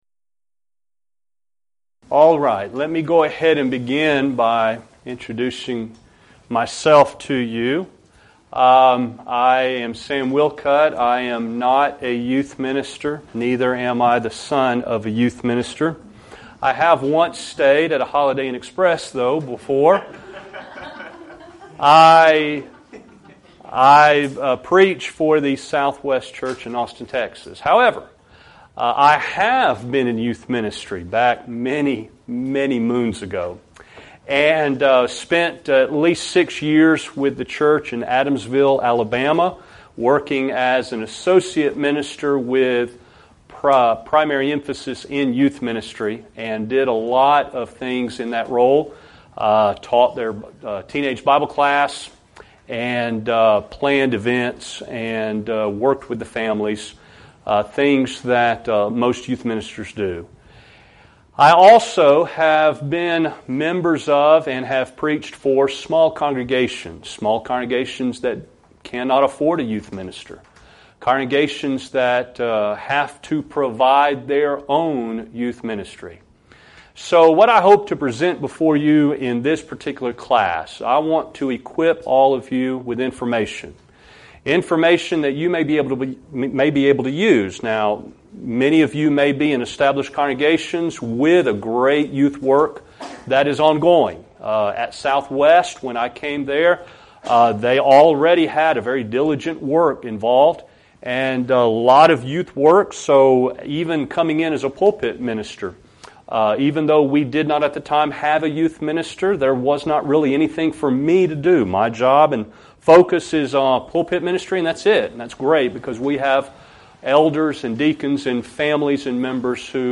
Event: 2015 Discipleship University
lecture